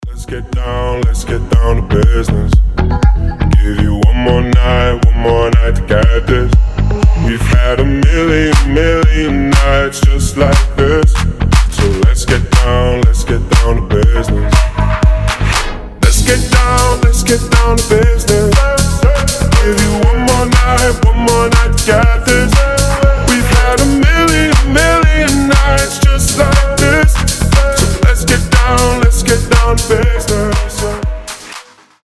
• Качество: 320, Stereo
deep house
красивый мужской голос
чувственные